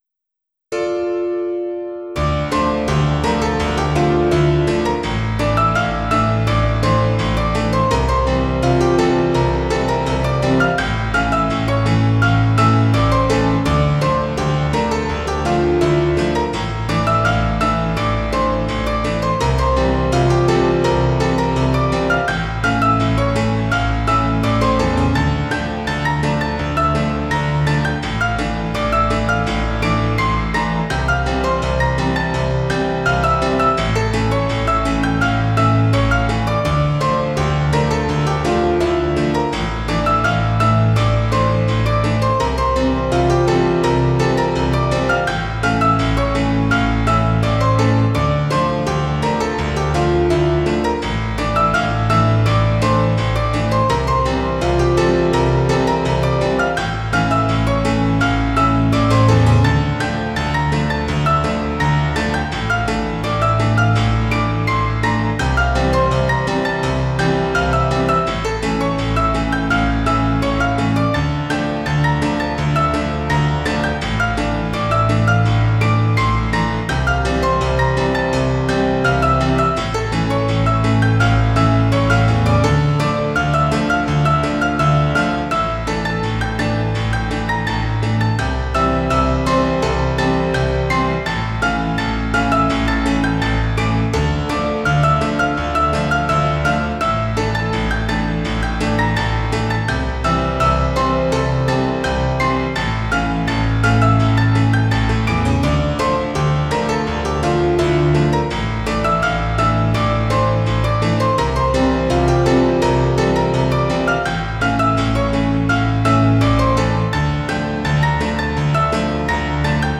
music / PIANO D-G